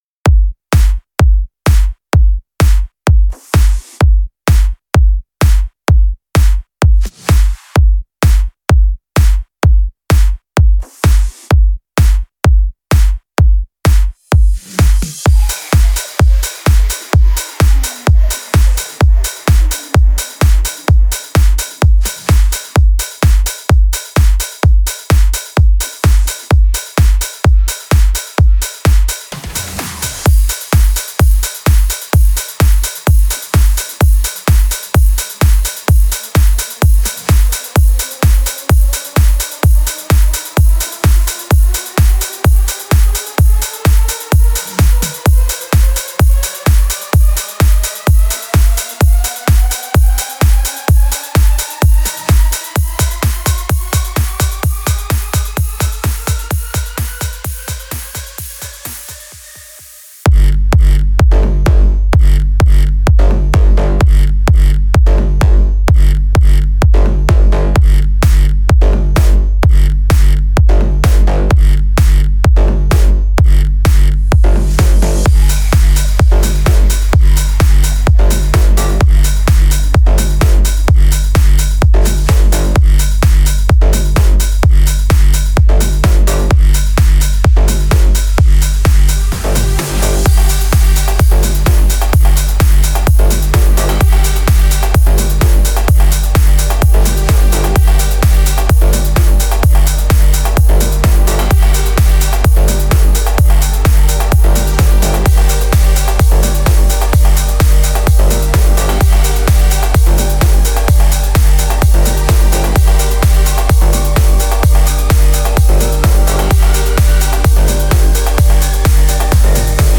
Стиль: Progressive Trance